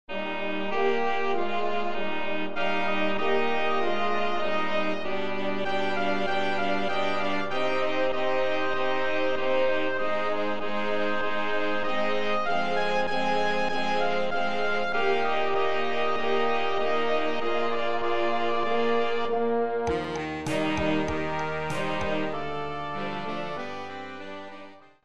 Rozrywkowa